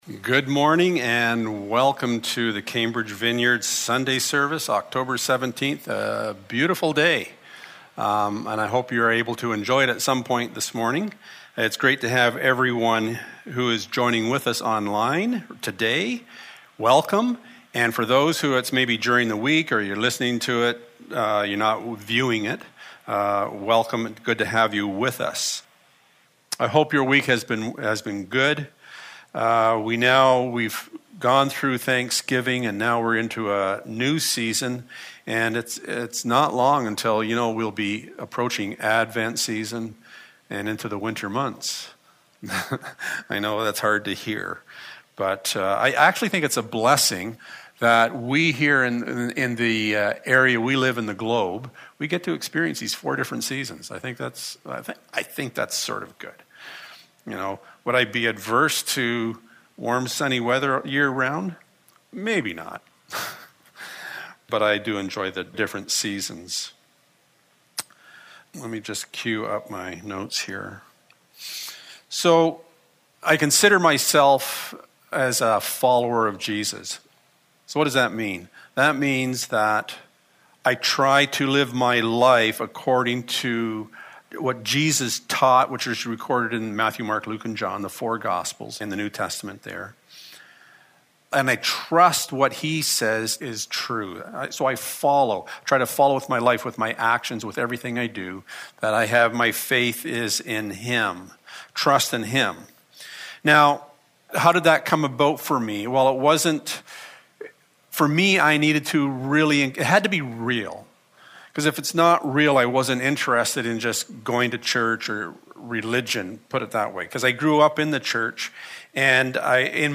38-40 Service Type: Sunday Morning Amid all the noise in our world